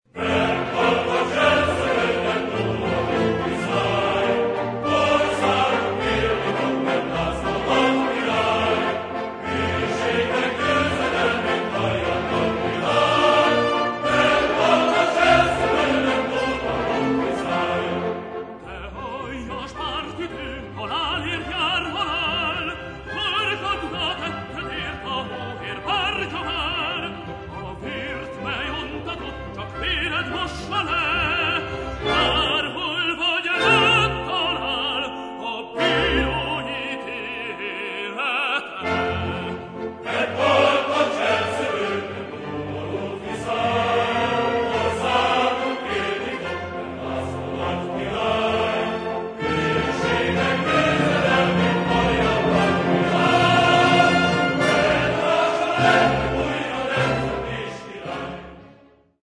The recording was made in Budapest, in 1984..
Chorus, King